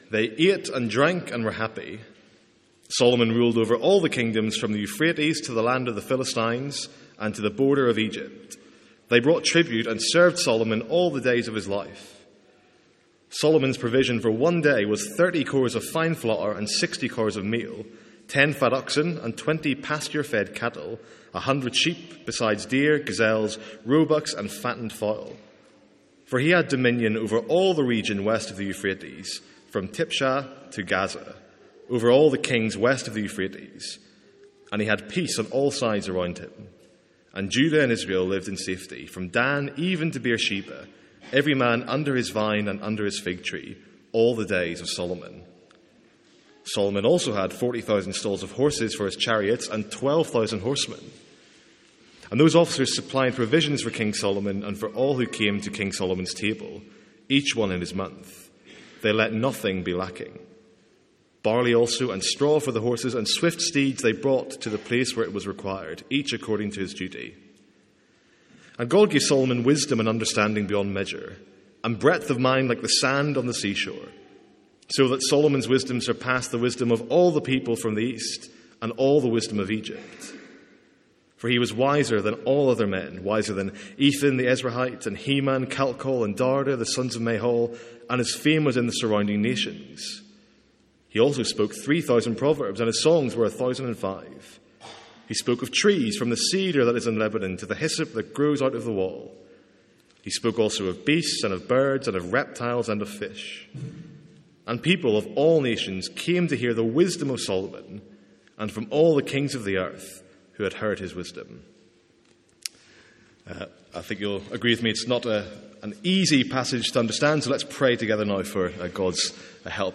Sermons | St Andrews Free Church
From our evening series in 1 Kings.